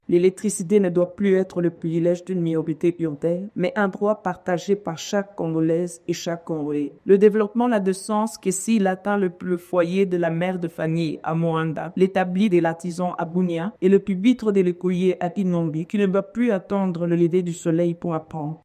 Dans son discours, la directrice générale de l’ARE, Soraya Aziz , a paraphrasé le Chef de l’État, déclarant : « Les ténèbres ne règneront pas toujours en RDC ».